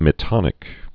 (mĭ-tŏnĭk)